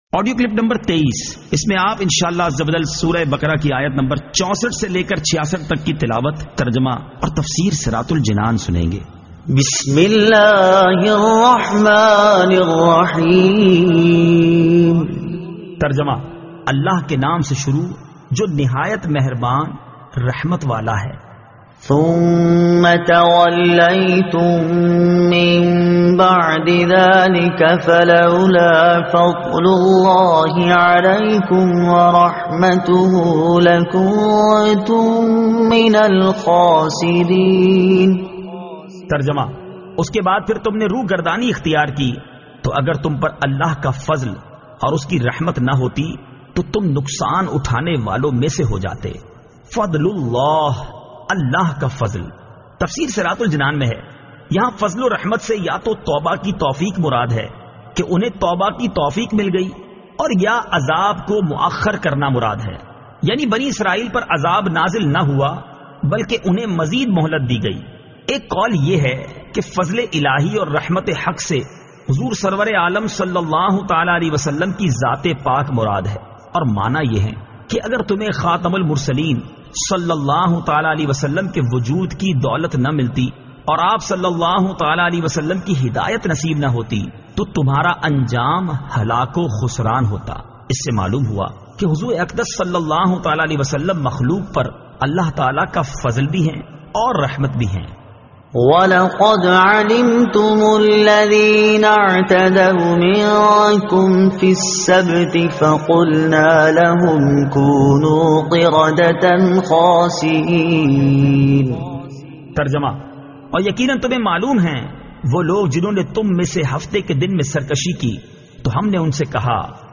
Surah Al-Baqara Ayat 64 To 66 Tilawat , Tarjuma , Tafseer